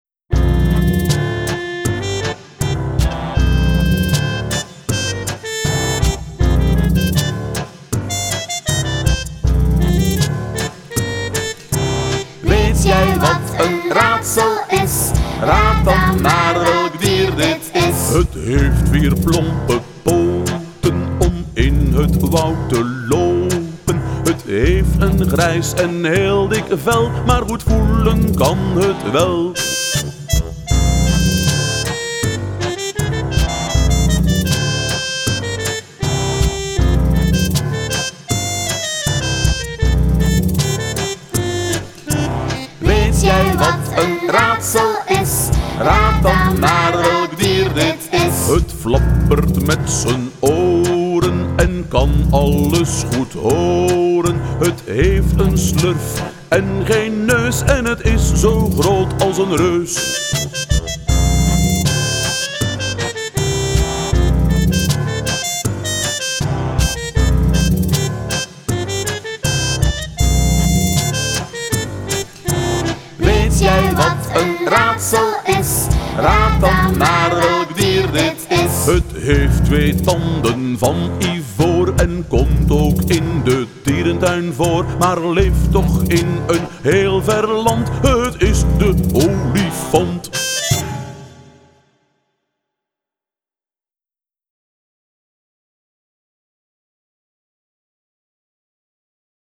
Hier is het liedje over een dierenraadsel dat we in de klas geleerd hebben.